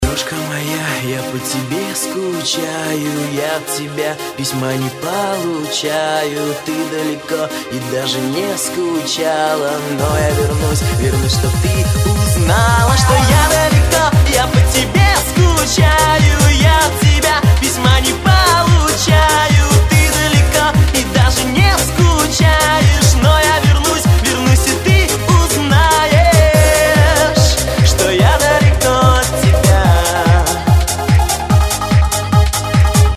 Танцевальные рингтоны
Евродэнс , Euro house